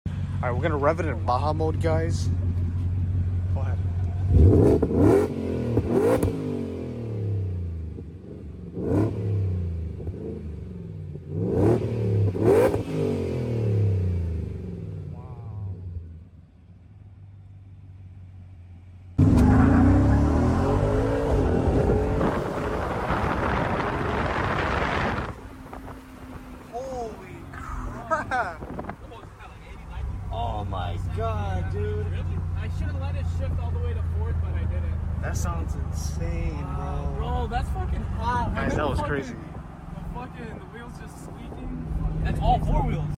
2025 Ford Raptor R Exhaust sound effects free download
2025 Ford Raptor R Exhaust sounds so good! I just wish it was a bit louder!